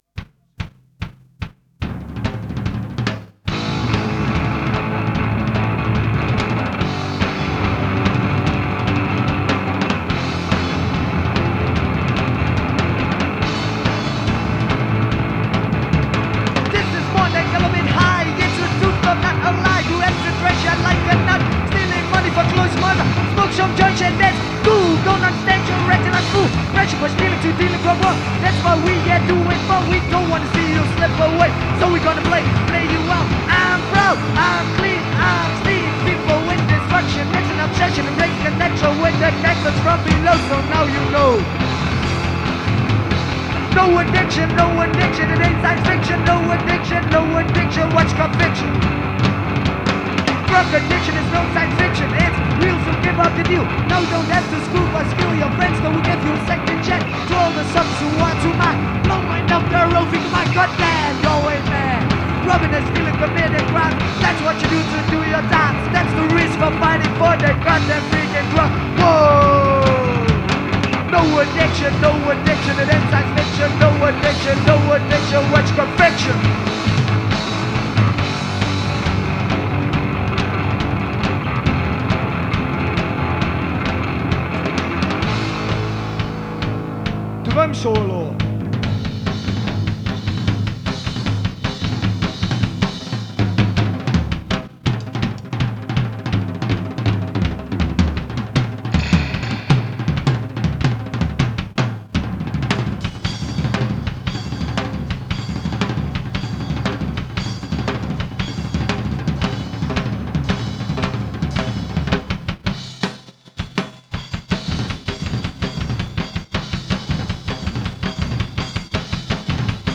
Onderdeel van Social behaviour - Live in Atak Enschede